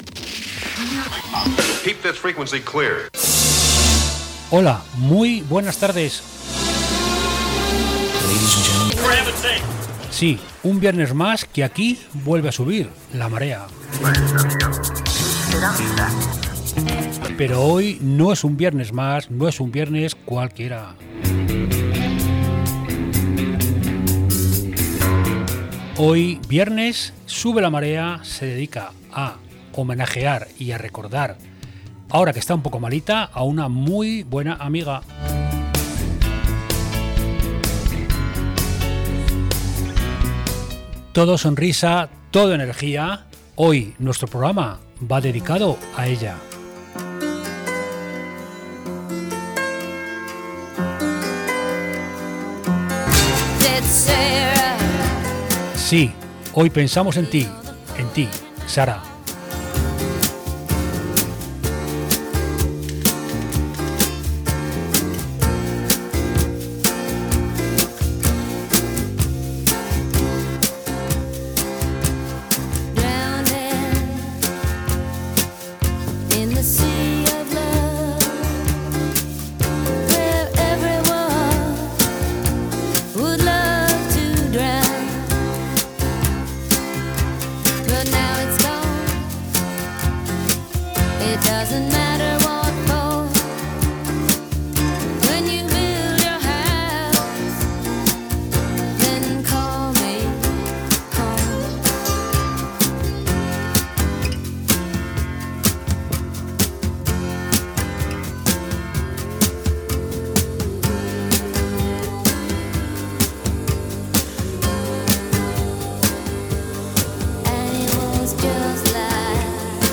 Y hemos pensado en hacer un recorrido por el mas puro sonido Británico que tanto conoce y radia.